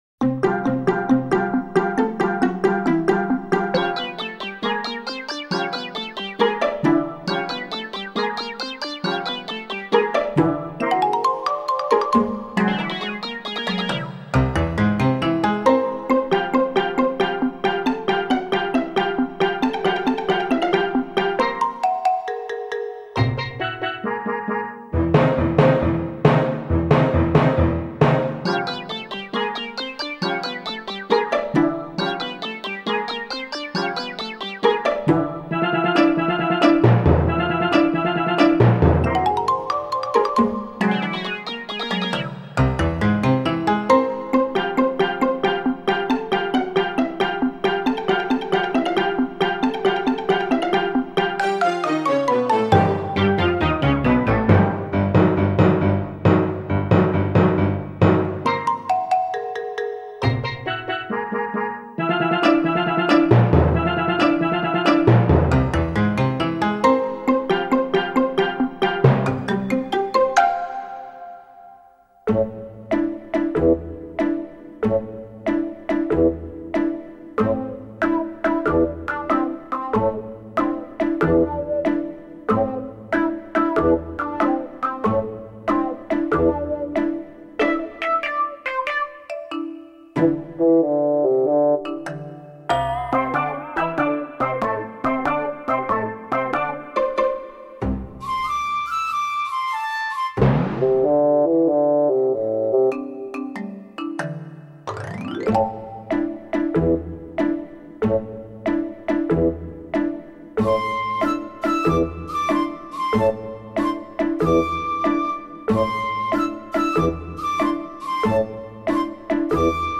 背景音乐为动漫欢快搞笑的人物配乐
该BGM音质清晰、流畅，源文件无声音水印干扰